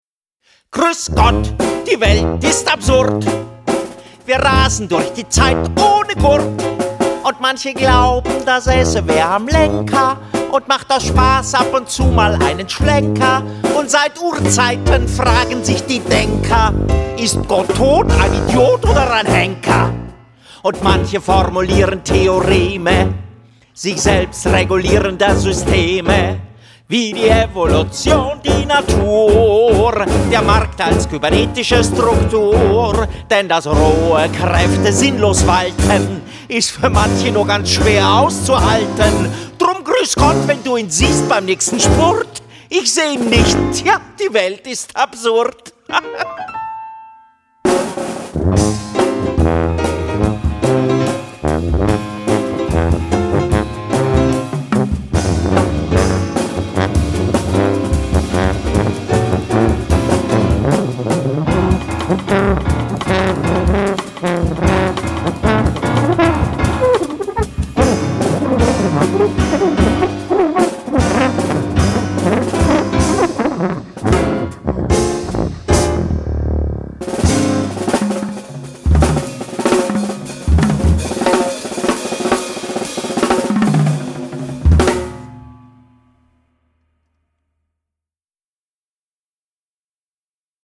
Klavierstimme